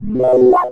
sci-fi_small_warp_machine_01.wav